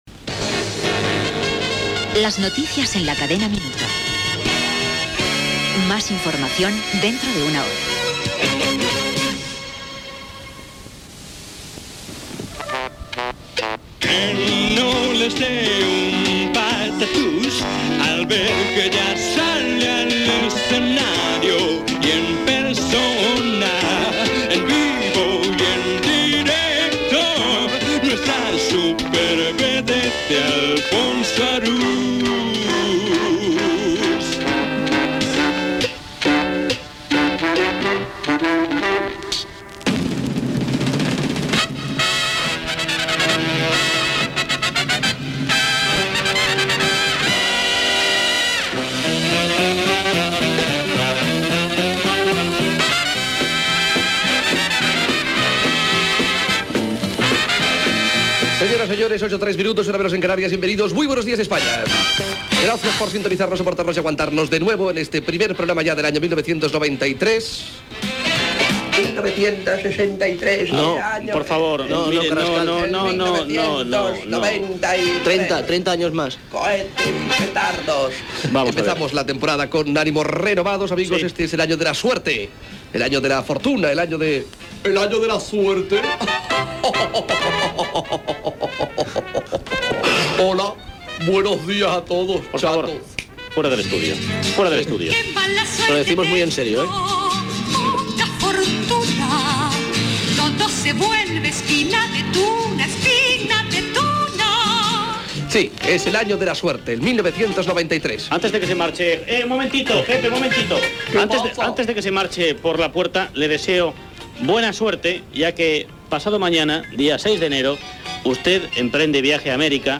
Careta de sortida de les notícies. Sintonia, presentació del programa, salutcions diverses, comentari sobre el cantant Pepe Vélez, equip
Entreteniment